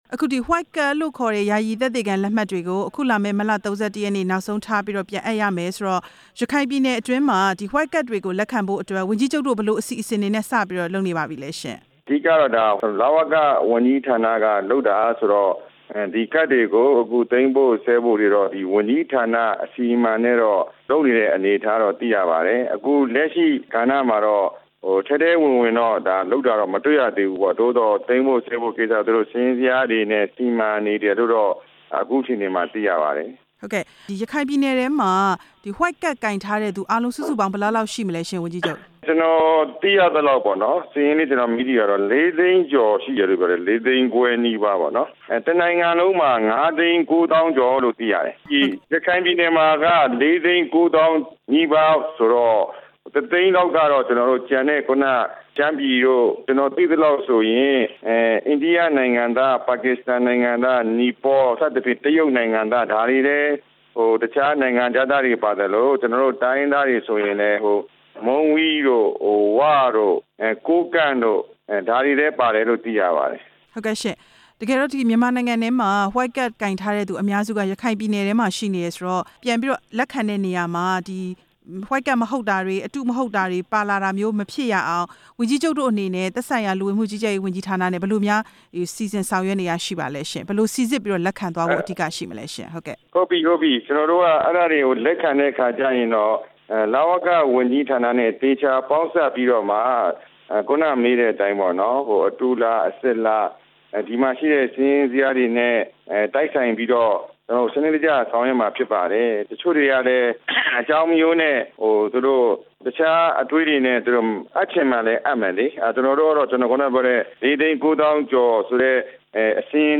ဝှိုက်ကဒ်အရေး ရခိုင်ဝန်ကြီးချုပ် နဲ့ မေးမြန်းချက်